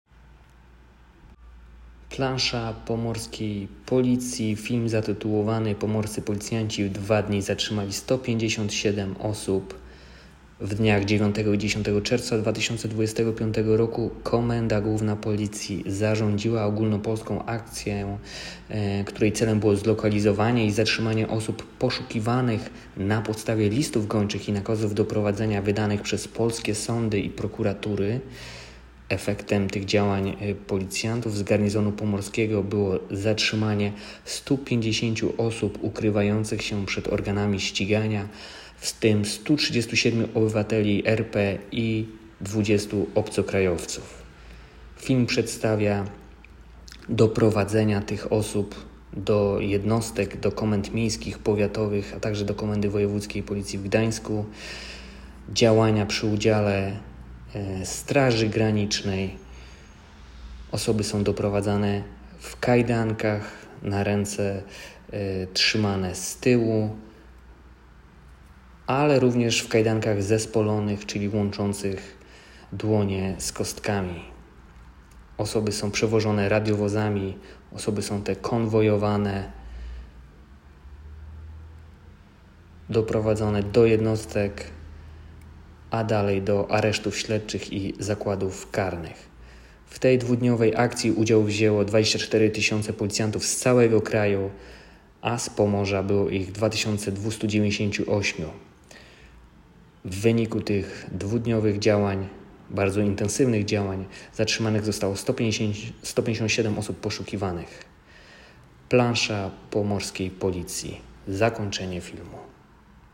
Nagranie audio Audiodyskrypcja